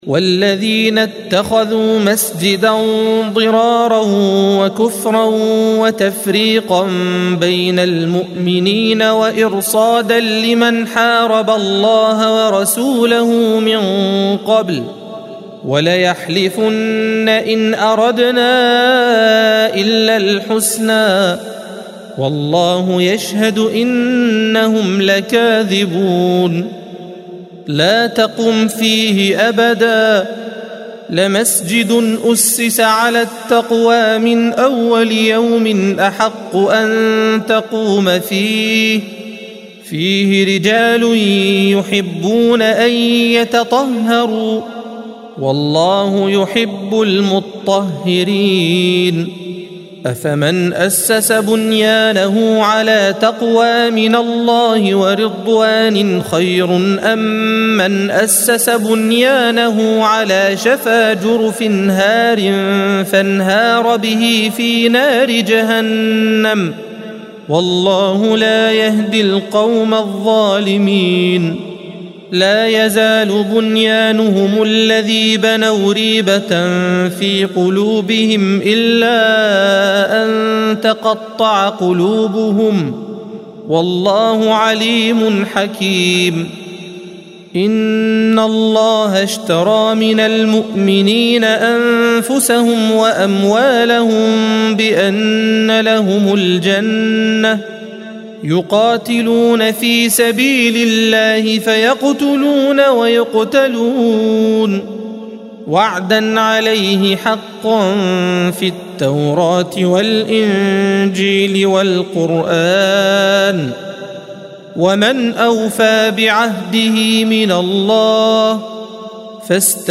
الصفحة 204 - القارئ